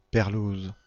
Ääntäminen
Synonyymit pet Ääntäminen France: IPA: /pεʀ.luz/ Haettu sana löytyi näillä lähdekielillä: ranska Käännöksiä ei löytynyt valitulle kohdekielelle.